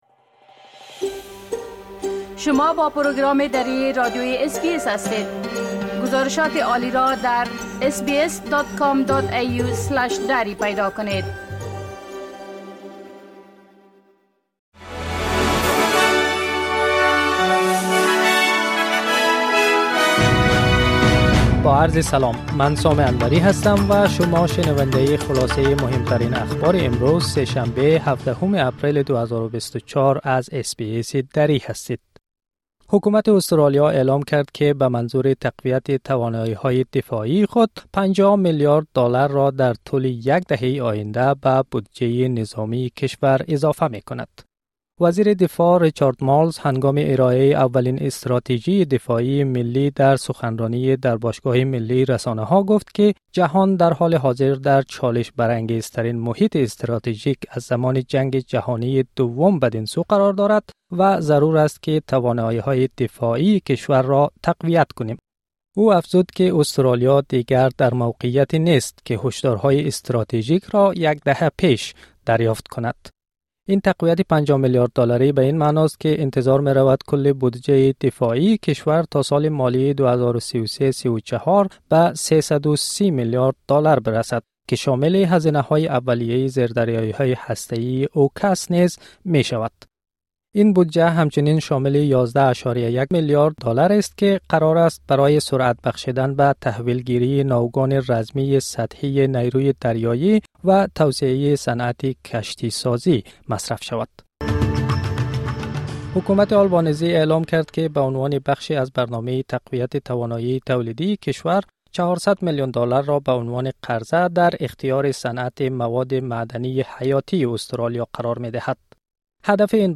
اخبار روز: از تزریق ۵۰ میلیارد دالر به بودجه نظامی آسترالیا تا تعلیق نشرات دو تلویزیون در افغانستان
حکومت آسترالیا با اشاره به تنش‌های استراتژیک فزاینده در جهان، از تقویت ۵۰ میلیارد دالری بودجه نظامی خود خبر داده است. همزمان، گروه طالبان از تعلیق نشرات دو شبکه تلویزیونی در کابل خبر داده‌اند. خلاصه اخبار مهم امروز (۱۷ اپریل ۲۰۲۴) را از اس‌بی‌اس دری بشنوید.